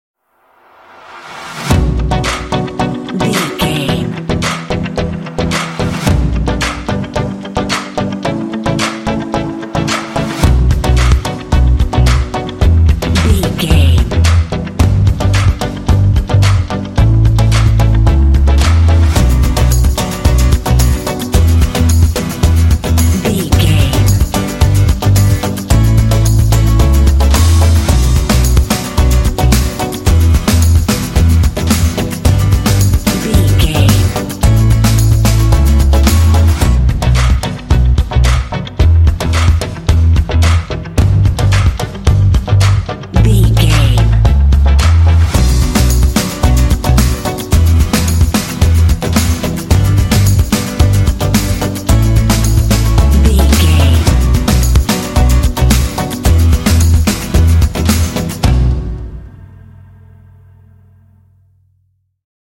Aeolian/Minor
energetic
dramatic
synthesiser
drums
acoustic guitar
bass guitar
alternative rock
indie